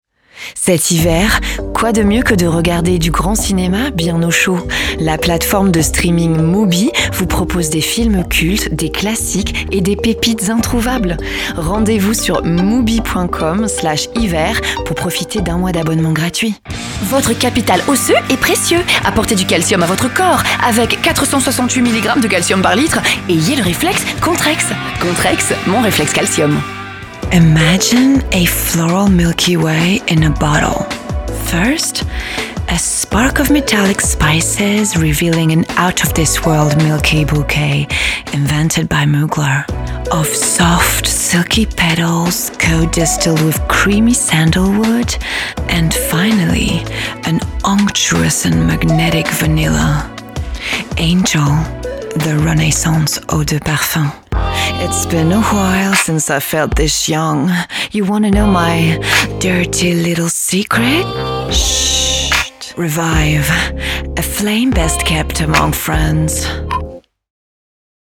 Bandes-son
Voix off
26 - 50 ans - Mezzo-soprano